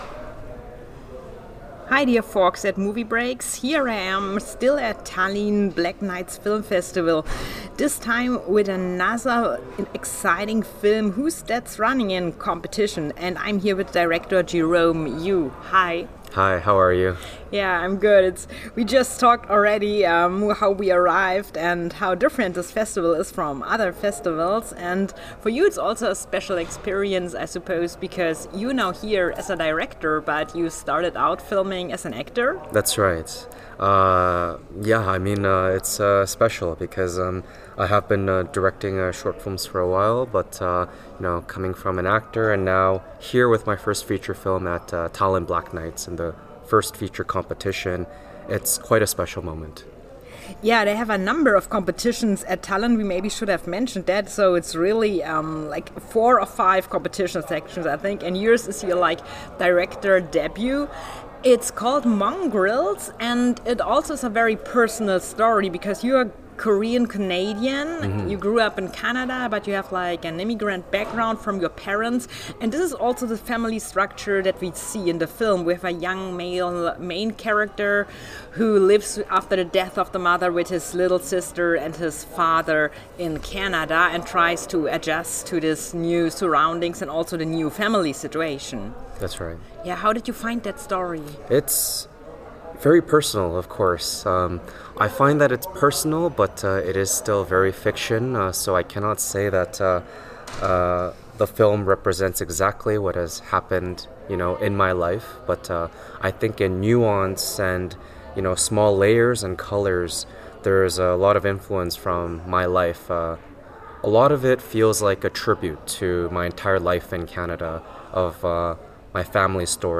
Tallinn Black Nights Film Festival 2024 - Interview